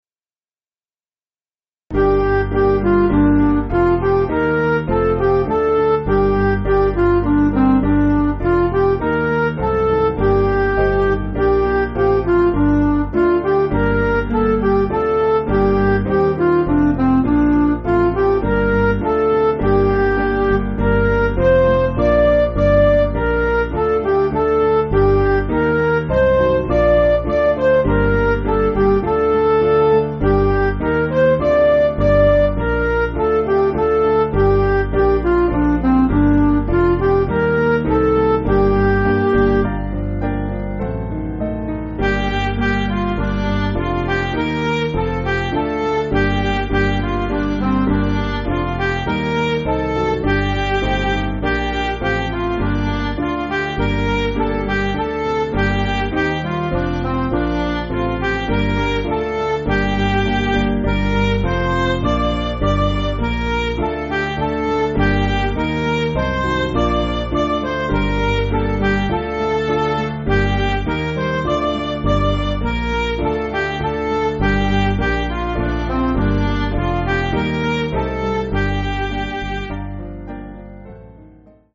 Piano & Instrumental
(CM)   5/Gm